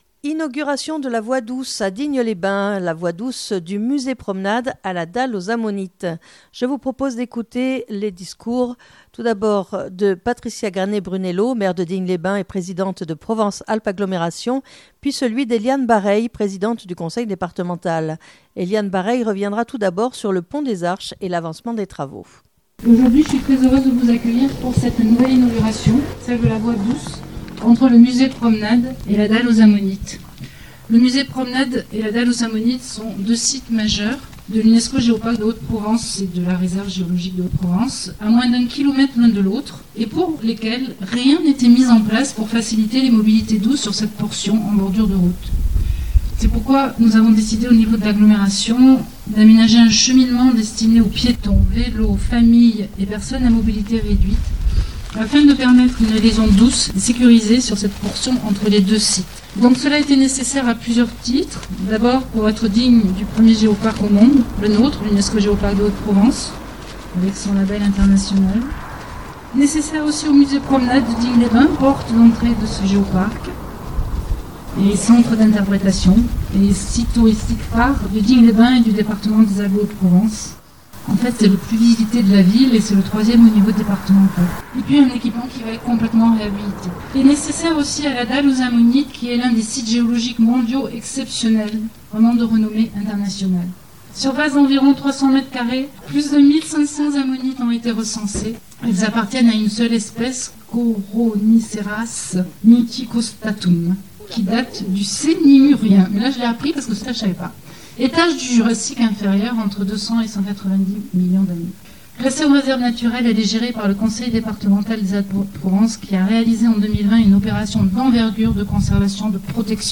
Je vous propose d'écouter le discours de Patricia Granet-Brunello maire de Digne les bains, Présidente de Provence Alpes Agglomération puis celui d'Eliane Barreille Présidente du Conseil Départemental. Eliane Barreille reviendra tout d'abord sur le Pont des Arches et l'avancement des travaux.